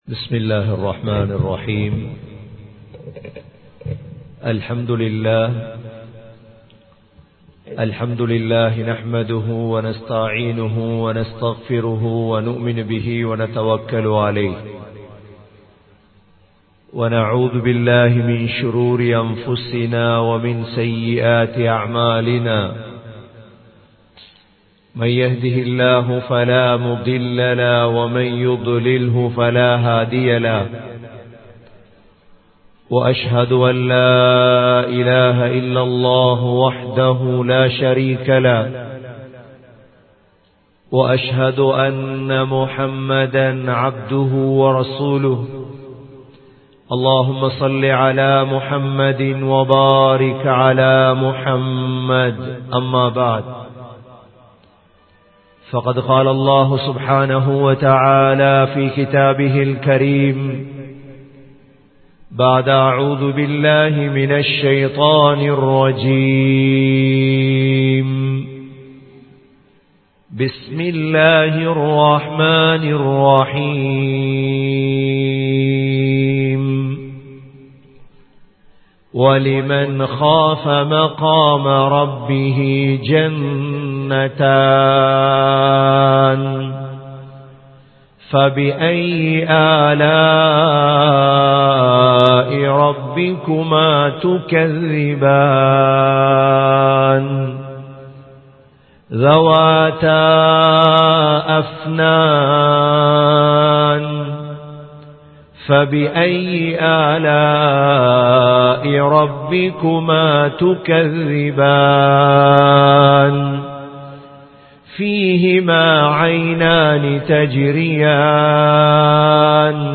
Surah Ar Rahman | Audio Bayans | All Ceylon Muslim Youth Community | Addalaichenai